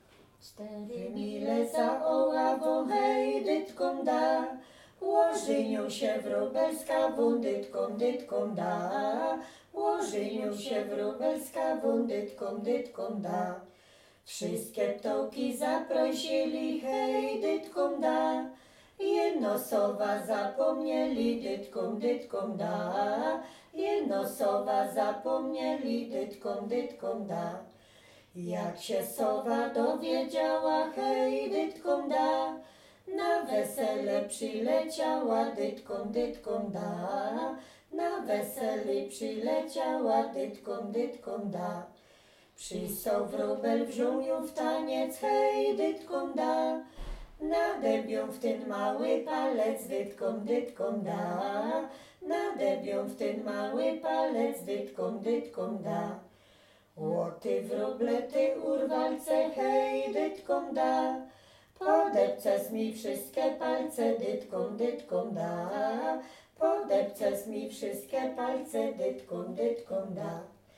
Śląsk Opolski
Kolęda